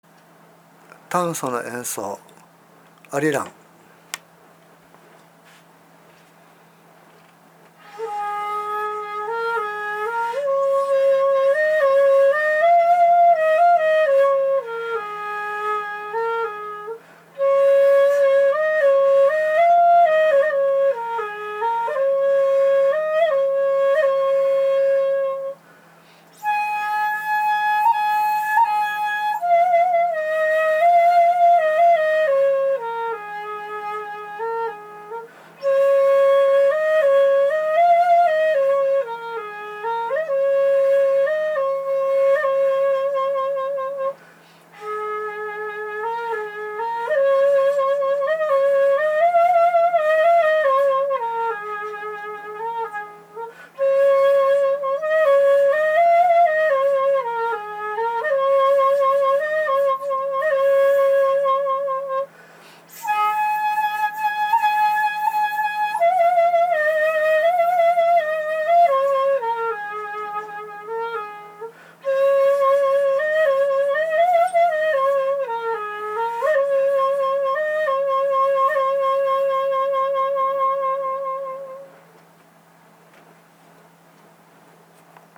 一節切も江戸時代に入るまでは普通に「尺八」と呼ばれていましたのでやはり短簫と音色が似ています。
これを短簫で吹奏してみます。
（短簫の「アリラン」音源）